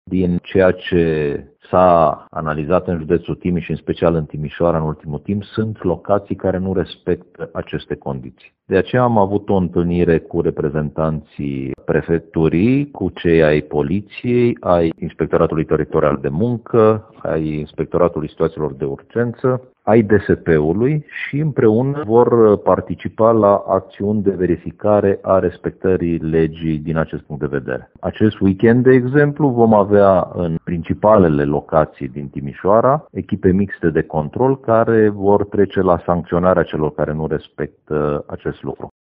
Subprefectul Ovidiu Drăgănescu mai spune că numărul maxim de persoane permis în fiecare club ar trebui să se regăsească în autorizația de funcționare, pe care firmele sunt obligate să le afișeze la loc vizibil.